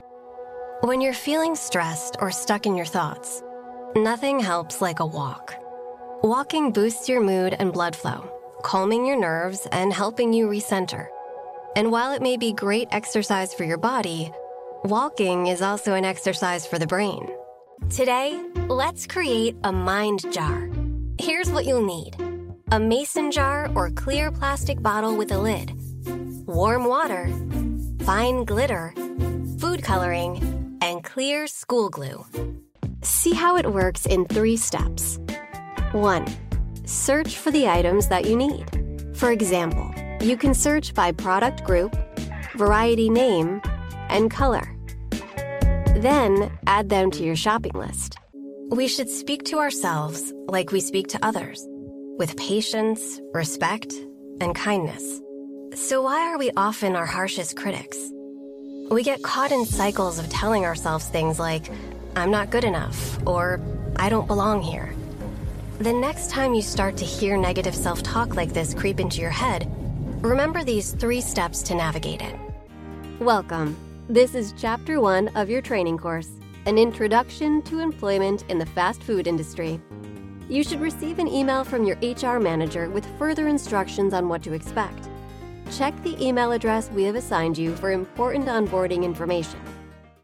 Female
American English (Native)
My voice sits in the millennial / Gen Z range – from early 20s to 40s, with a General American accent.
Corp Narr:Image Film Demo_2.mp3
Microphone: Sennheiser MKH416